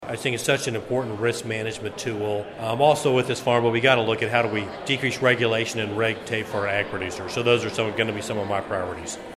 Congressman Tracey Mann met with constituents Tuesday at the Wefald Pavilion in City Park, part of his ongoing listening tour across the Big First district.